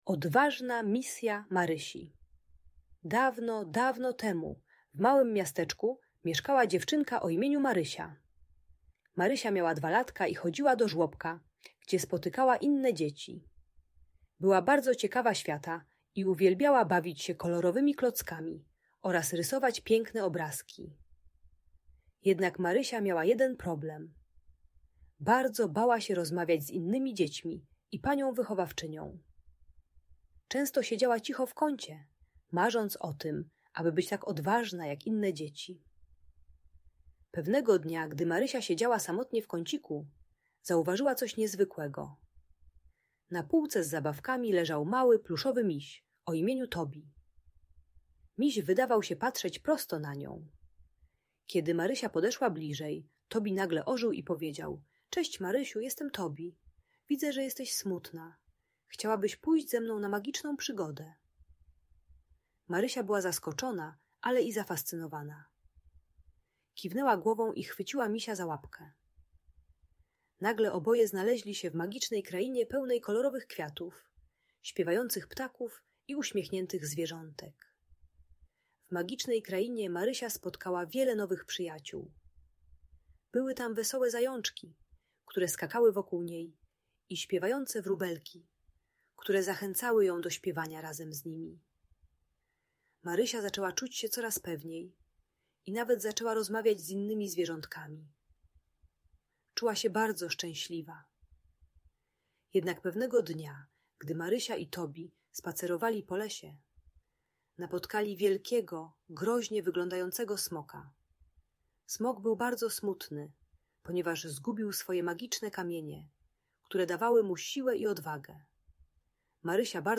Odważna Misja Marysi - story o przygodzie i odwadze - Audiobajka